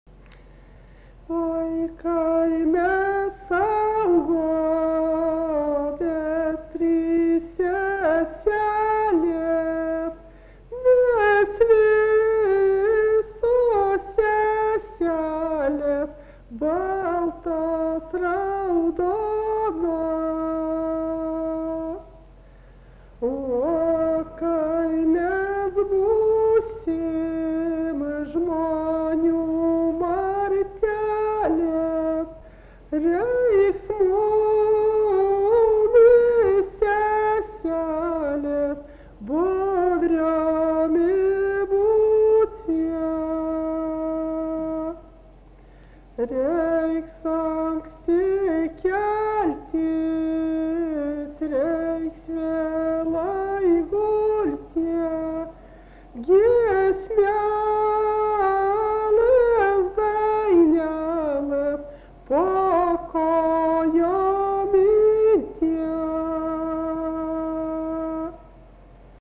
Dalykas, tema daina
Erdvinė aprėptis Rudamina Mečiūnai
Atlikimo pubūdis vokalinis